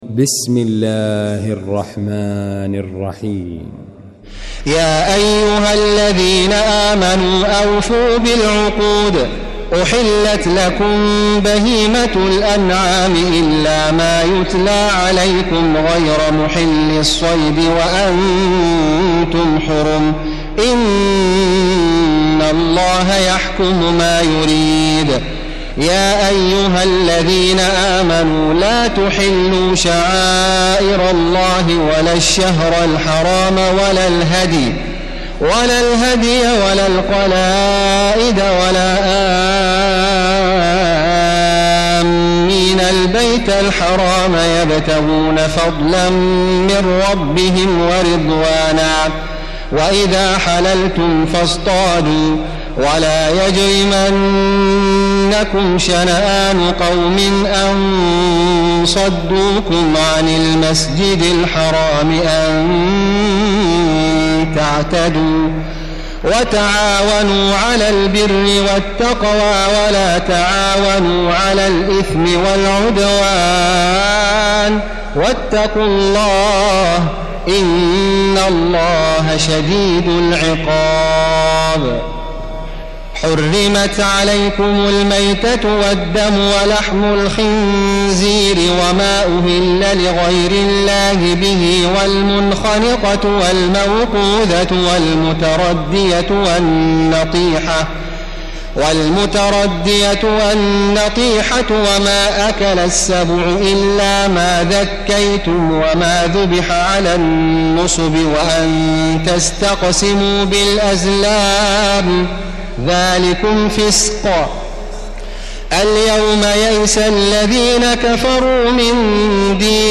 المكان: المسجد الحرام الشيخ: معالي الشيخ أ.د. بندر بليلة معالي الشيخ أ.د. بندر بليلة خالد الغامدي سعود الشريم المائدة